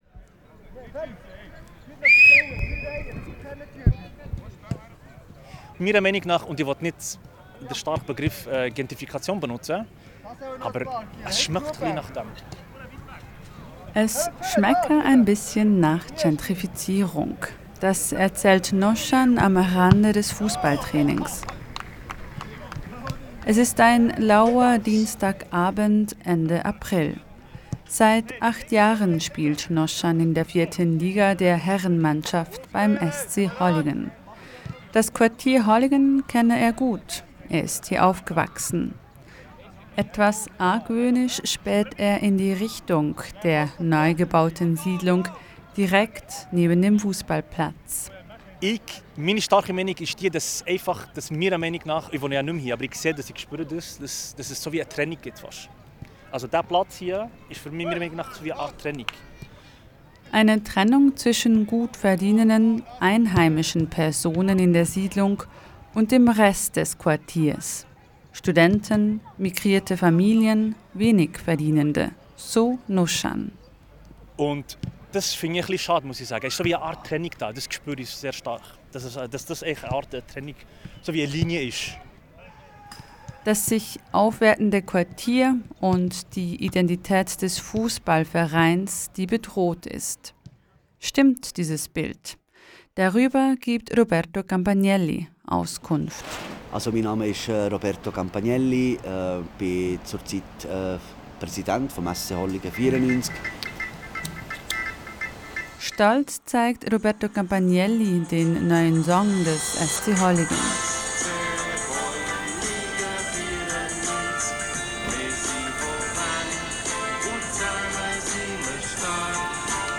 Dieser Beitrag ist eine Zusammenarbeit von RaBe Info und Journal B und erschien auch als Audioreportage bei Radio RaBe.